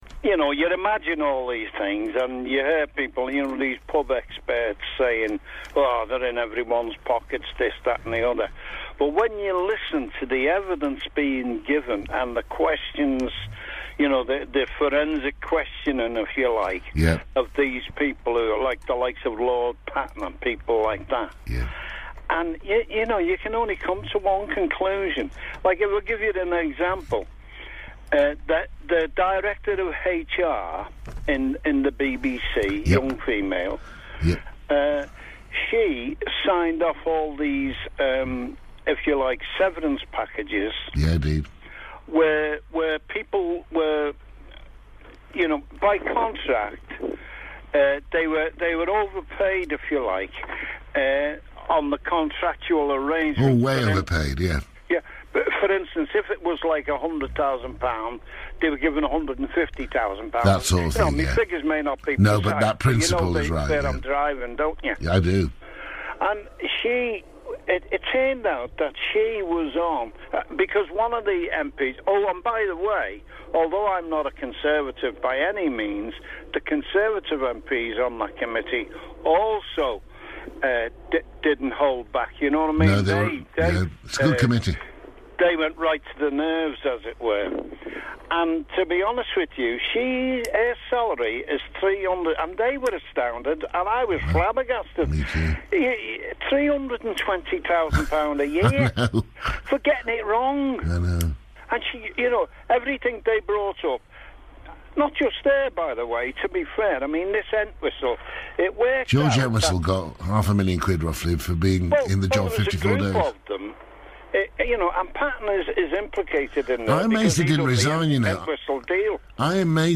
Caller Discuss BBC Severance